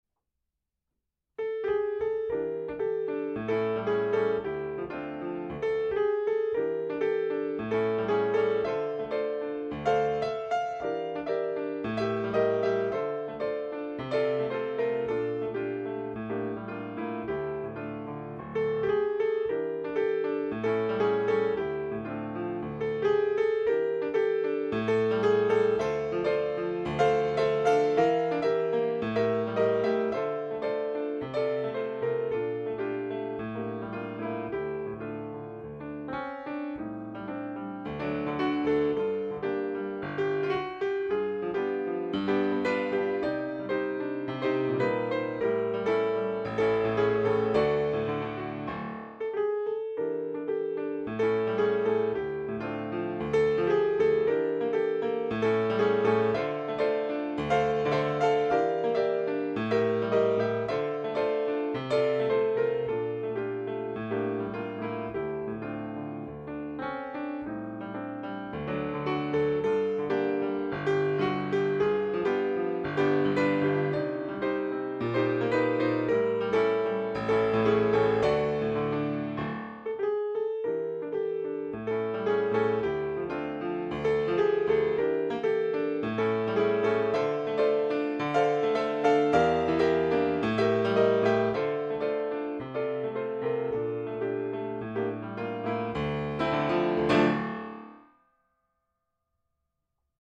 piano - jazzy - melancolique - nostalgique - melodique